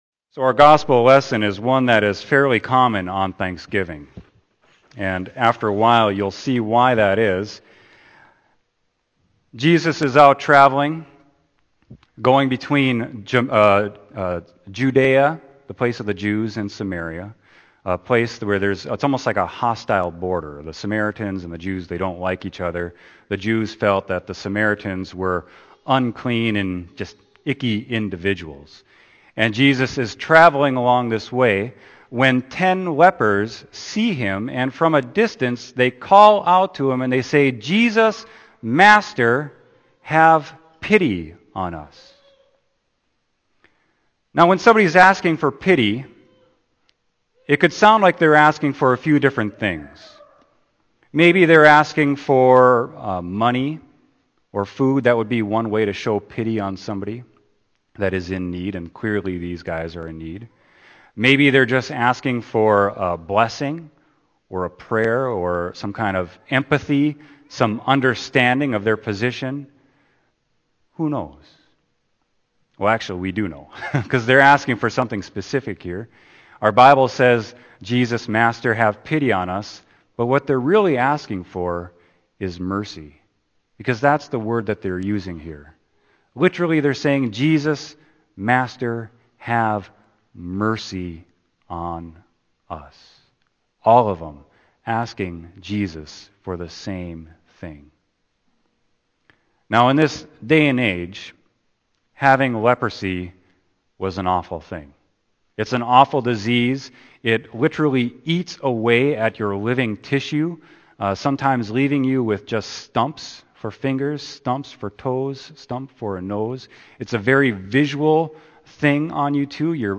Sermon: Luke 17.11-19